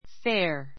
fair 1 A1 féə r ふェ ア 形容詞 ❶ 公正な, 公平な, 公明正大な; （スポーツで） ルールにかなった, フェアの fair play fair play 正々堂々のプレー a fair ball a fair ball フェアボール A teacher must be fair to all his students.